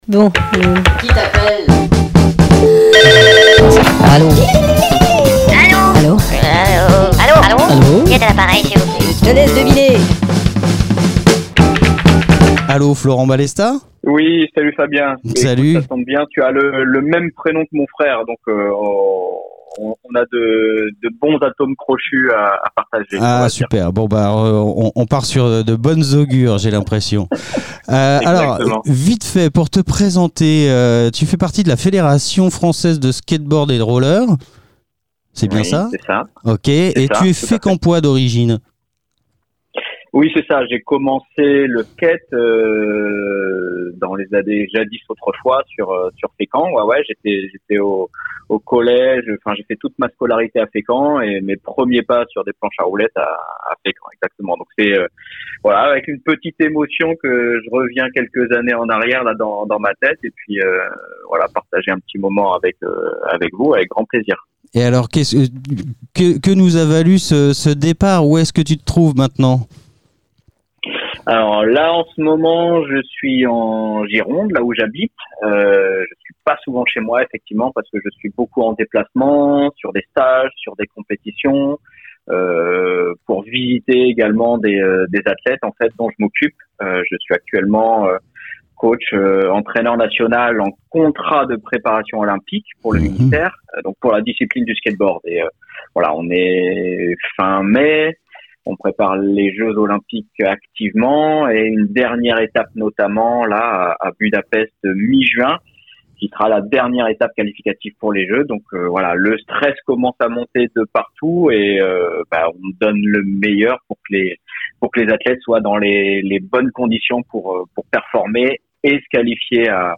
Pour mieux vous faire par de l'actu des artistes Normands, RADAR les appelle directement !
Interview ALLO téléphone téléphonique